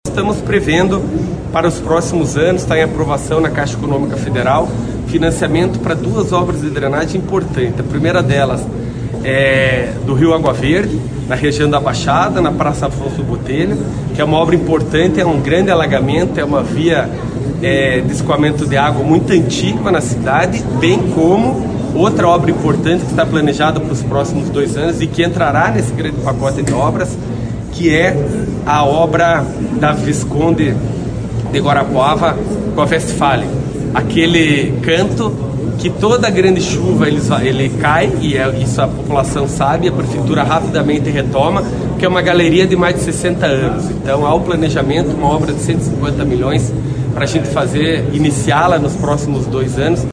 Segundo Pimentel, o projeto deve sair do papel nos próximos dois anos.
SONORA-PIMENTEL-OBRAS-3-GJ.mp3